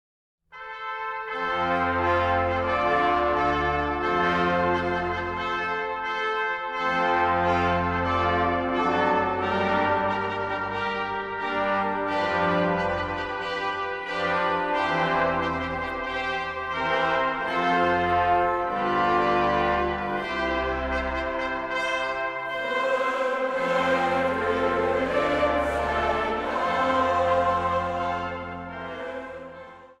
Zang | Gemengd koor
Siciliaanse traditional